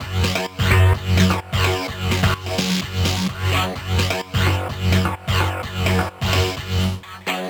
Nines_128_F#_FX.wav